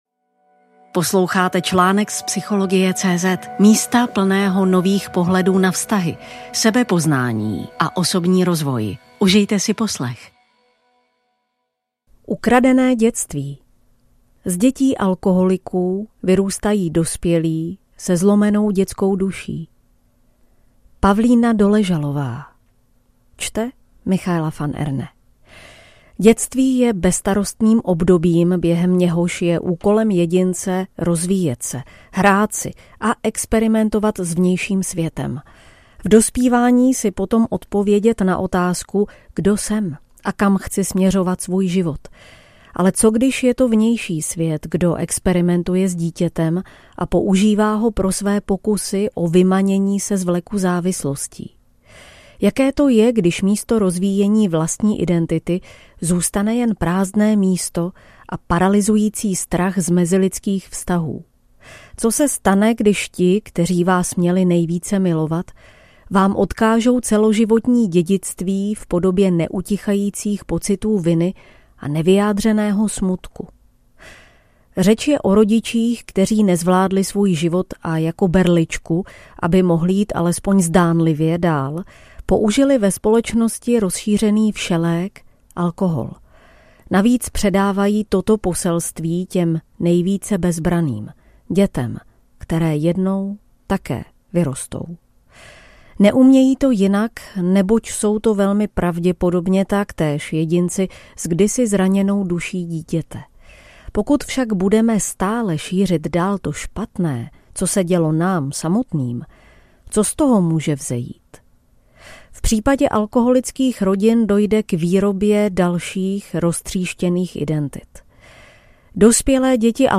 Audioverze